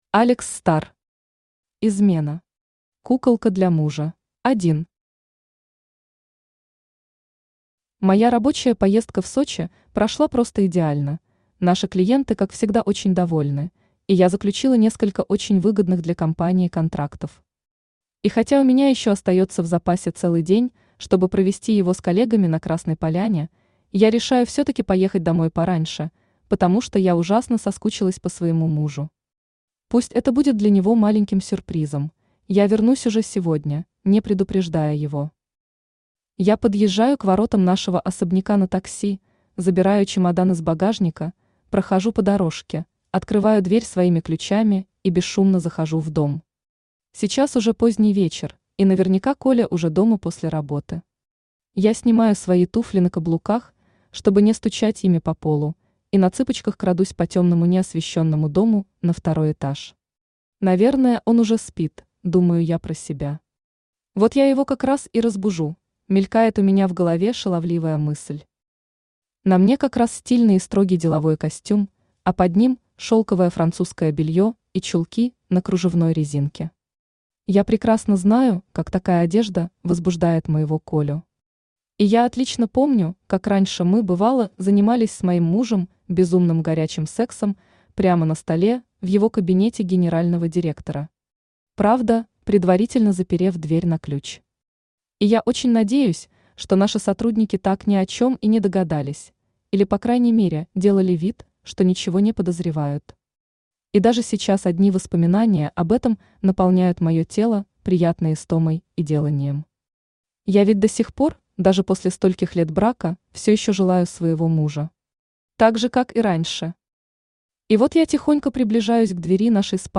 Аудиокнига Измена. Куколка для мужа | Библиотека аудиокниг
Aудиокнига Измена. Куколка для мужа Автор Алекс Стар Читает аудиокнигу Авточтец ЛитРес.